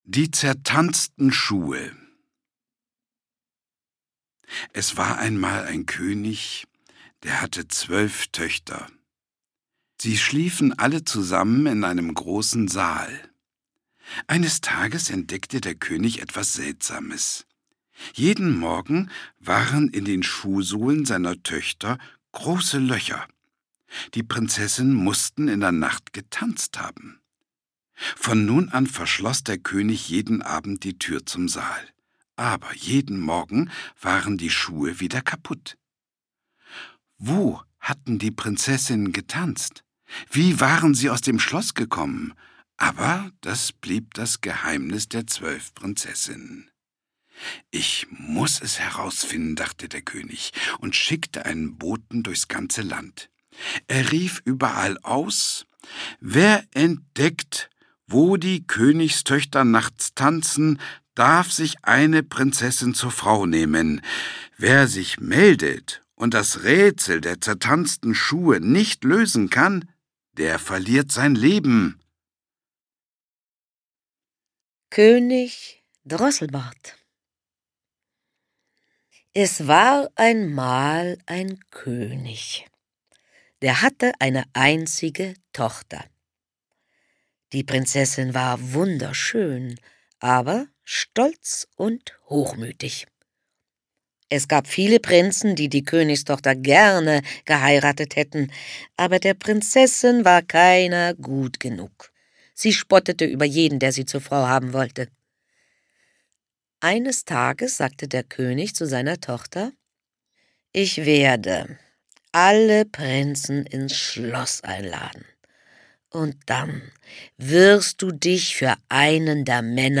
Schlagworte Geschichten • Hörbuch; Lesung für Kinder/Jugendliche • Hörbuch; Märchen/Sagen • Kinder/Jugendliche: Märchen, Sagen, Legenden • Märchen • Prinzessin